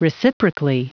Prononciation du mot reciprocally en anglais (fichier audio)
Prononciation du mot : reciprocally